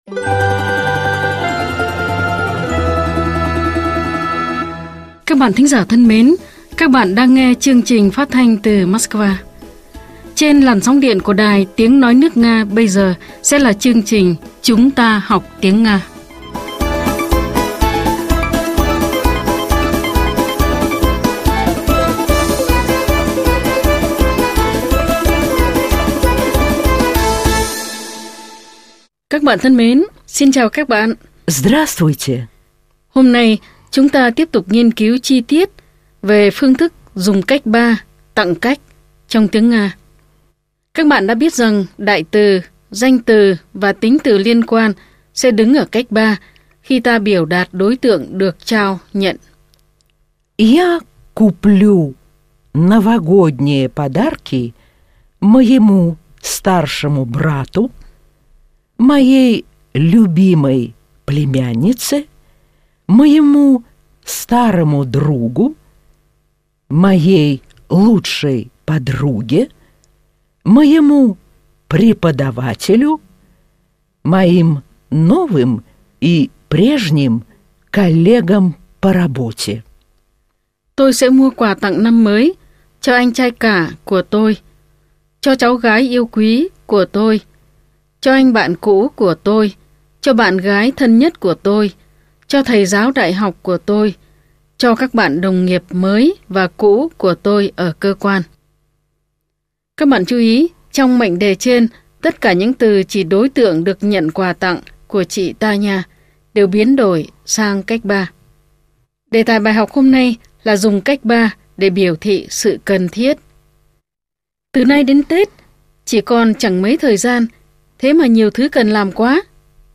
Bài 68 – Bài giảng tiếng Nga
Nguồn: Chuyên mục “Chúng ta học tiếng Nga” đài phát thanh  Sputnik